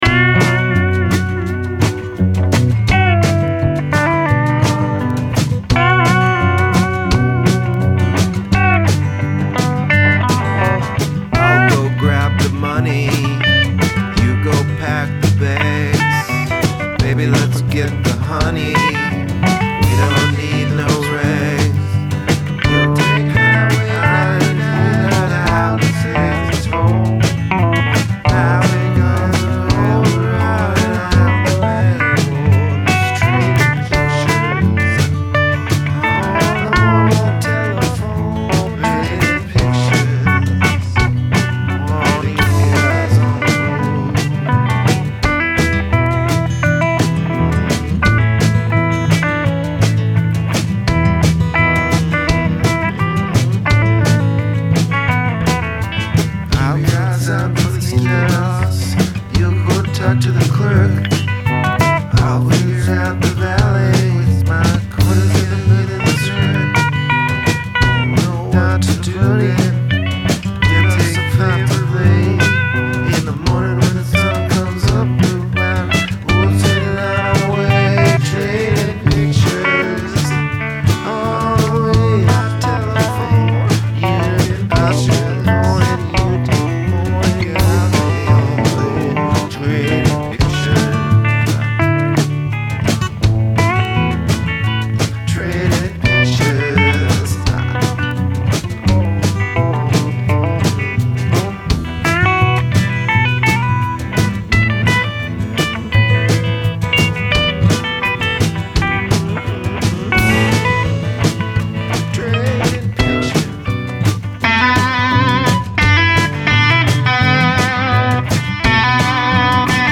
Contrasting dark & light [full lyrics] ..
85 BPM